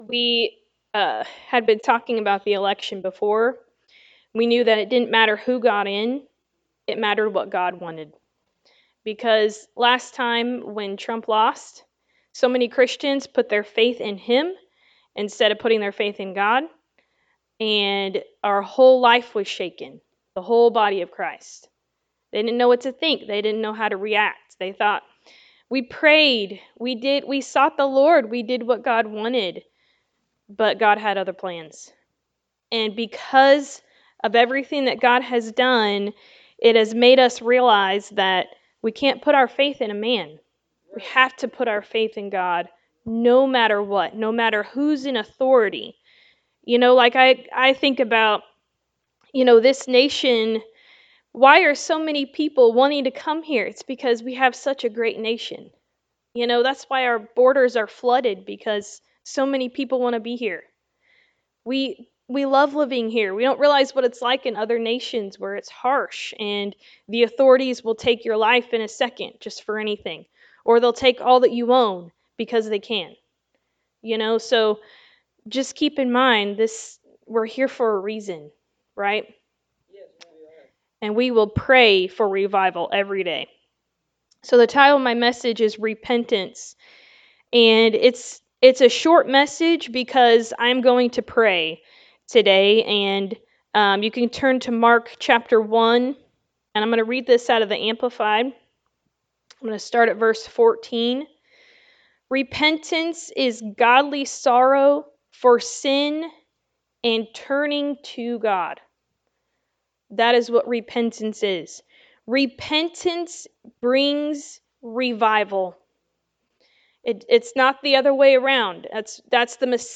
Mark 1:14-15 Service Type: Sunday Morning Service The election is over